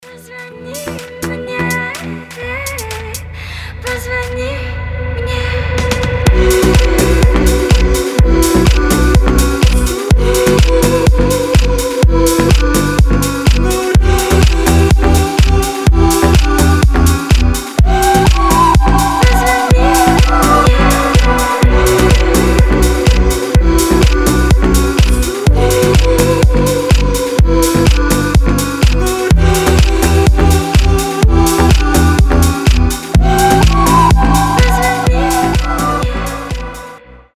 • Качество: 320, Stereo
deep house
атмосферные
мелодичные
басы
чувственные
восточные
красивый женский голос